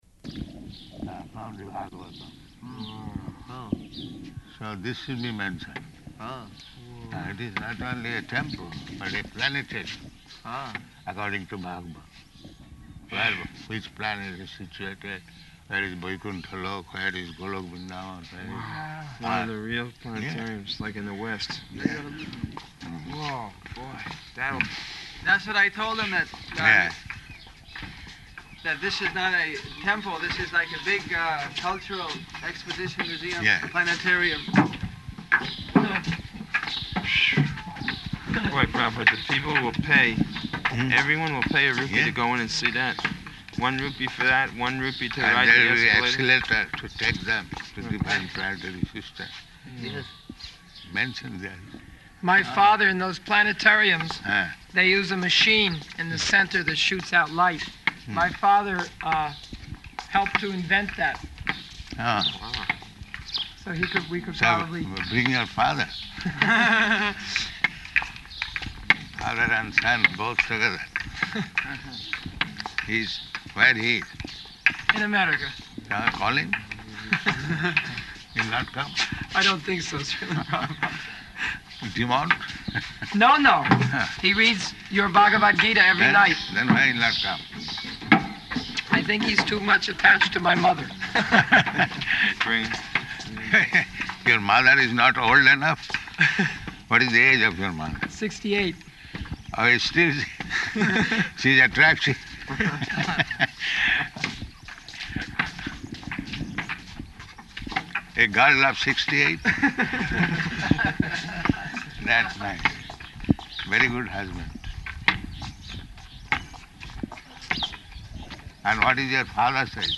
Morning Walk --:-- --:-- Type: Walk Dated: February 9th 1976 Location: Māyāpur Audio file: 760209MW.MAY.mp3 Prabhupāda: ...from the Bhāgavatam.